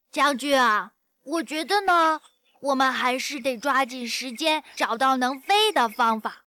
翻唱音色